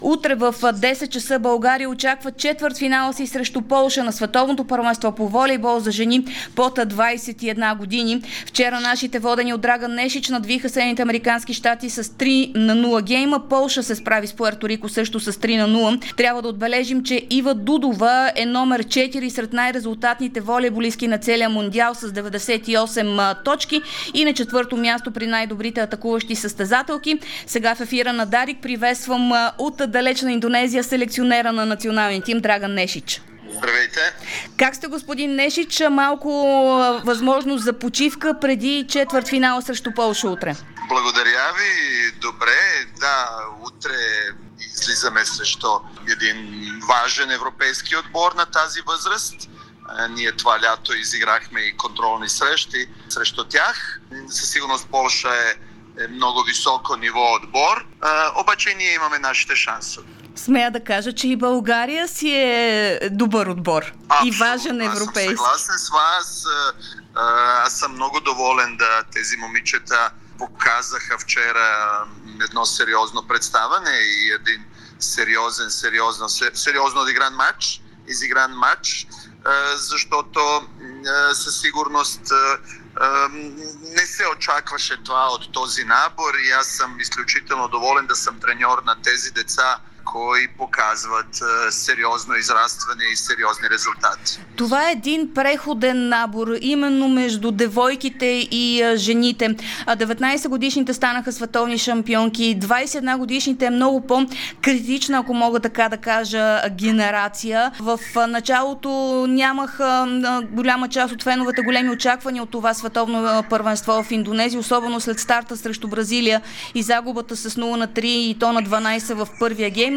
говори на живо от Индонезия в ефира на Дарик радио .